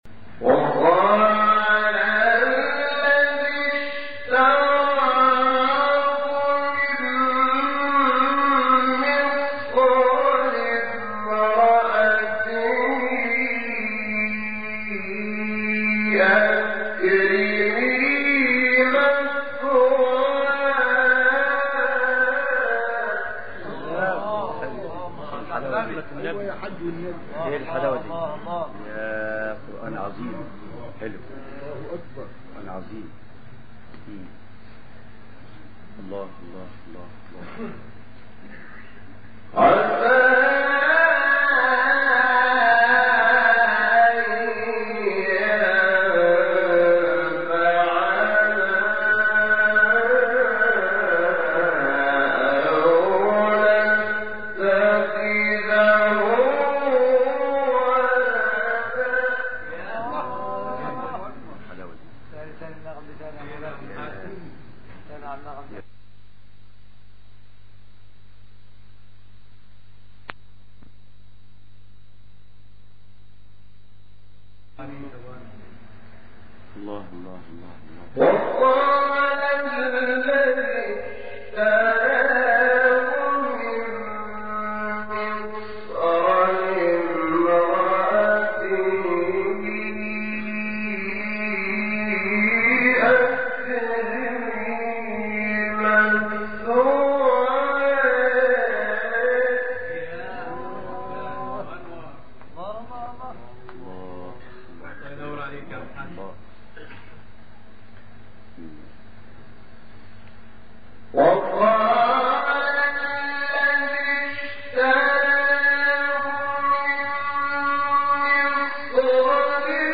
آیه 21 سوره یوسف شحات محمد انور | نغمات قرآن | دانلود تلاوت قرآن